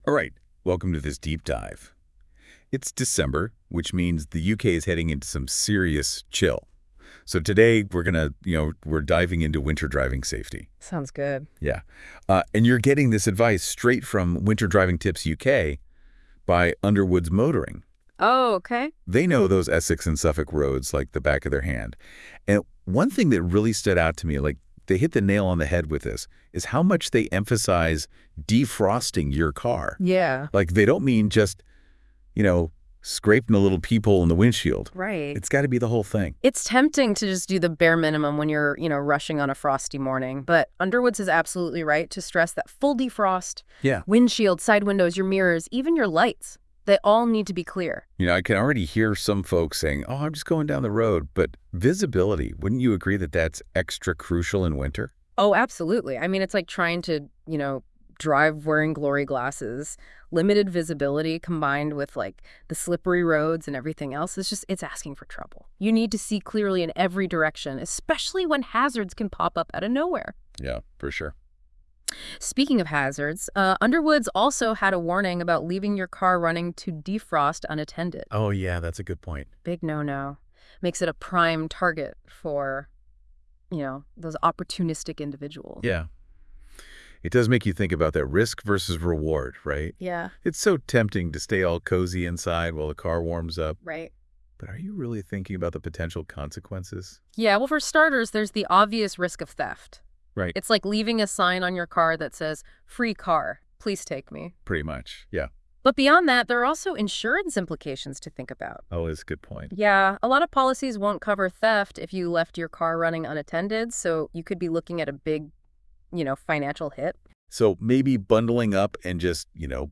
If you would prefer to hear this news blog generated as a conversational podcast by the astonishing powers of AI click here.